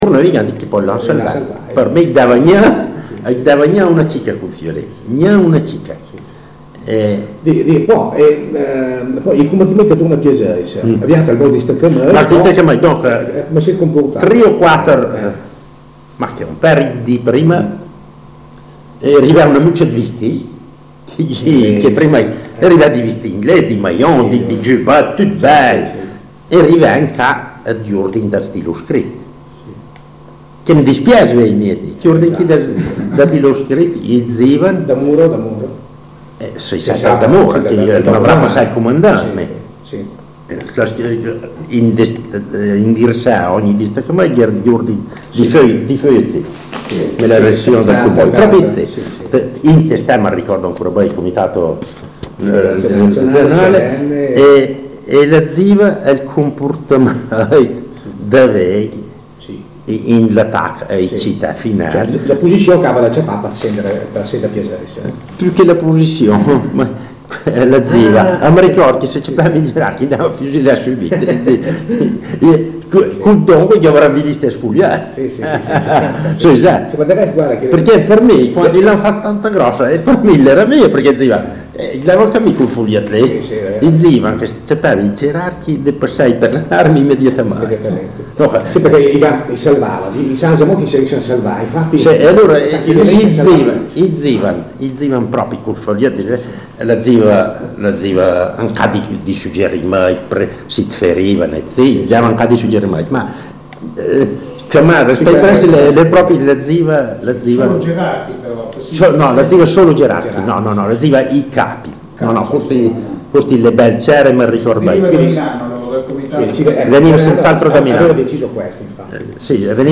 Le interviste, raccolte in una dozzina di audiocassette, si snodano per undici ore complessive, secondo una successione di momenti e di argomenti. L’esposizione, sebbene non sempre tecnicamente perfetta e con frequente ricorso al dialetto, permette, attraverso un eloquio semplice e spontaneo, senza remore di alcun genere, di usufruire di una ricca testimonianza, che va a costituire un prezioso deposito documentario presso l’ISREC di Piacenza.